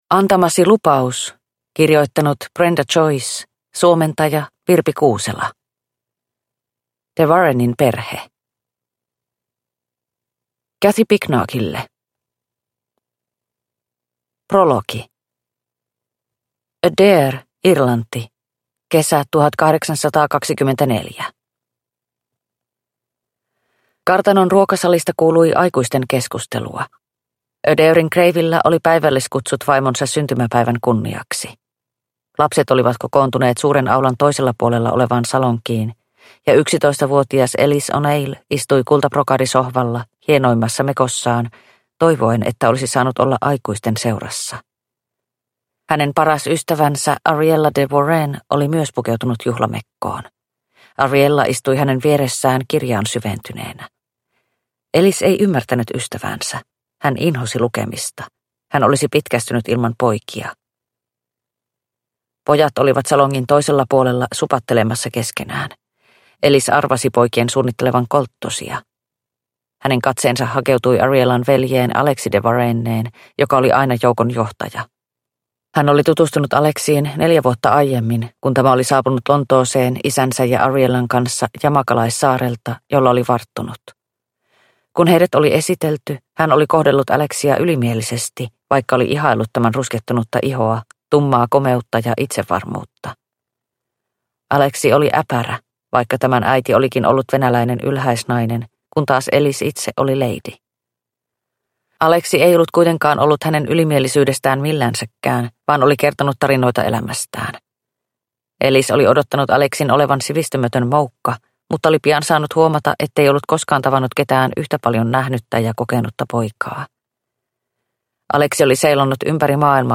Antamasi lupaus – Ljudbok – Laddas ner